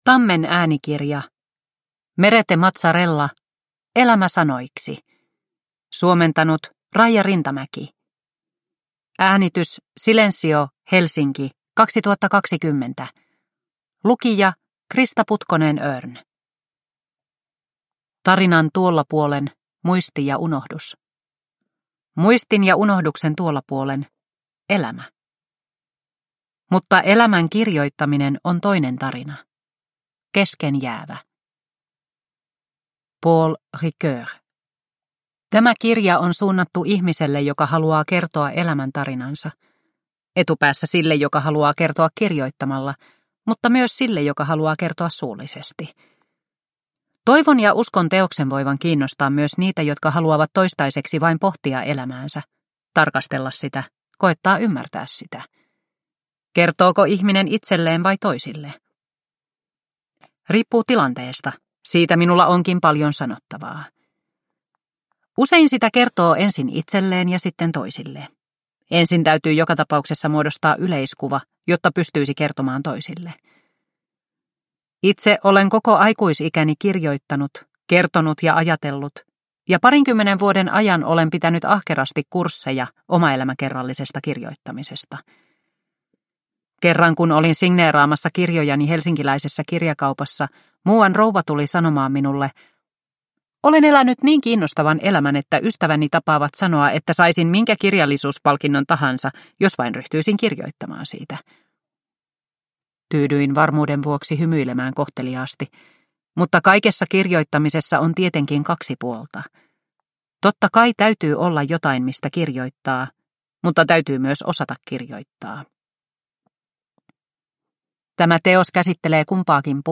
Elämä sanoiksi – Ljudbok – Laddas ner